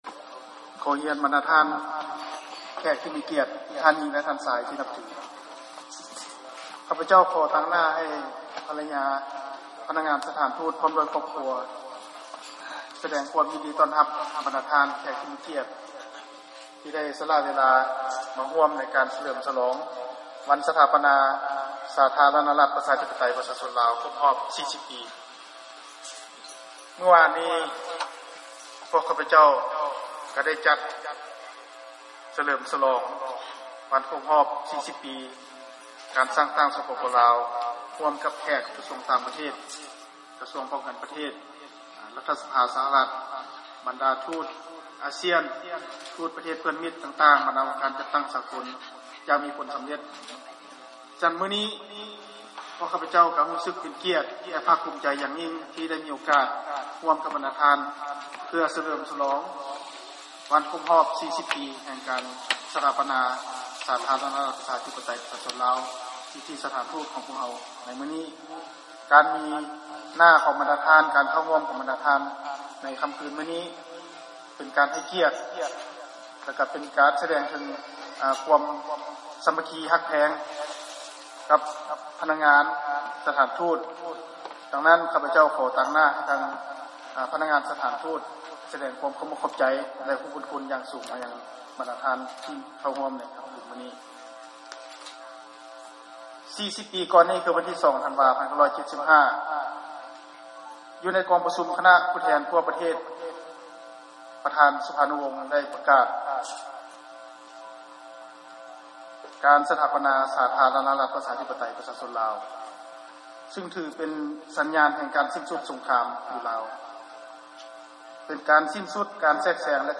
ເຊິນຟັງ ຄຳຖະແຫລງ ພະນະທ່ານ ເອກອັກຄະລັດຖະທູດ ໄມ ໄຊຍະວົງ ໃນວັນ ສະຫລອງວັນຊາດ ສປປ ລາວ ທີ 2 ທັນວາ